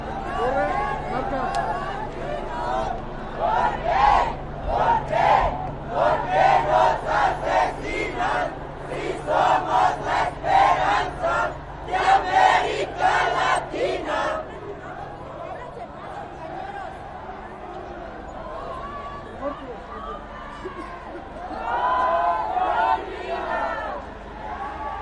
Tag: 人群 游行 示威 聚众